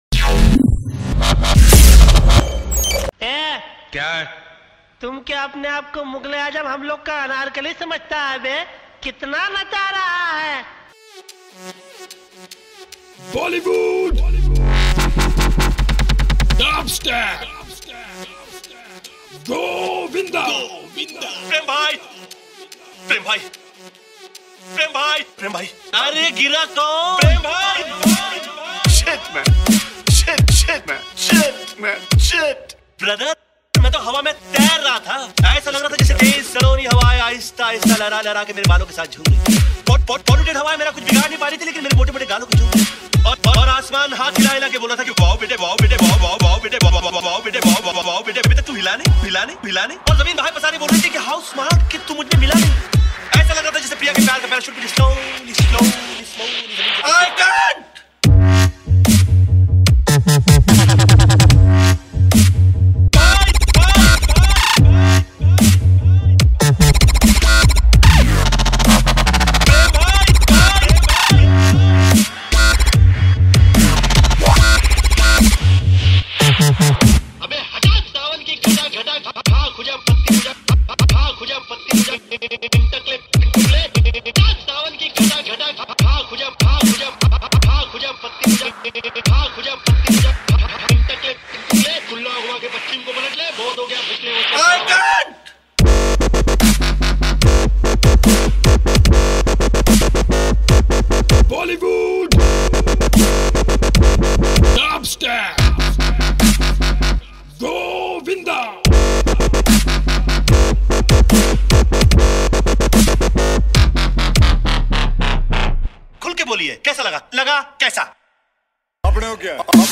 DJ Remix Mp3 Songs > DJ Dubstep Songs